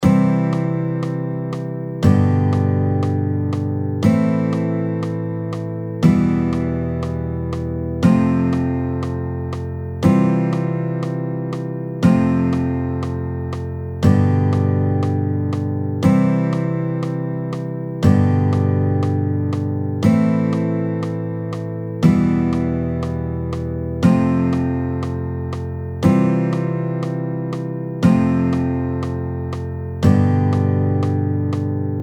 所謂有名なクラシックのカノン進行です！
Ⅰ-Ⅴ-Ⅵm-Ⅲm-Ⅳ-Ⅰ-Ⅳ-Ⅴ音源